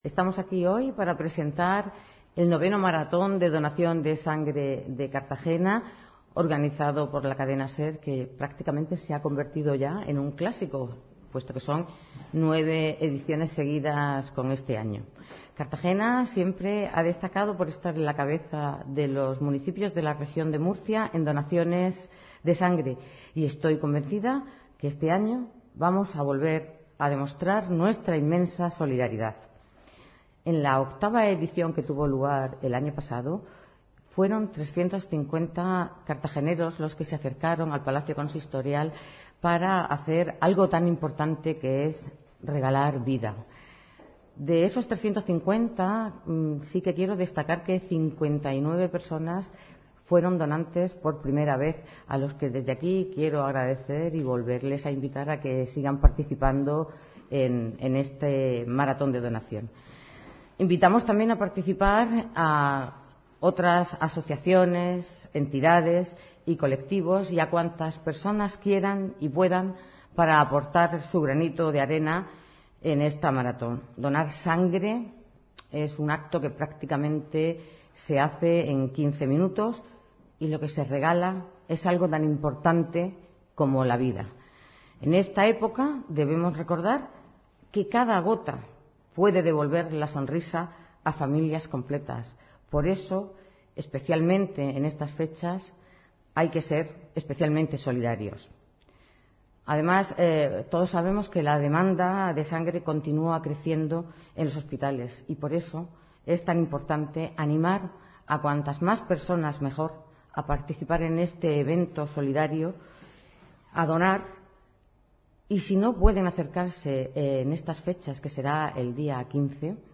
Audio: Presentaci�n del Marat�n de Sangre SER Solidarios (MP3 - 4,28 MB)